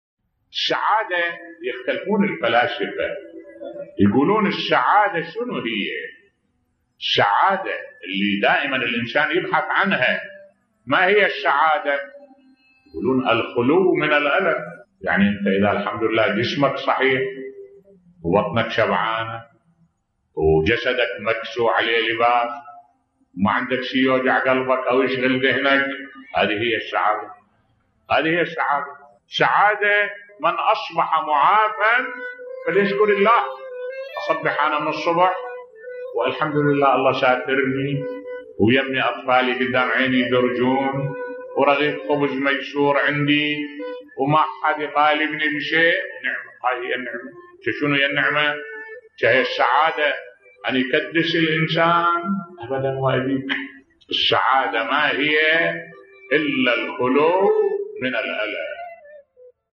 ملف صوتی السعادة التي يبحث عنها الإنسان بصوت الشيخ الدكتور أحمد الوائلي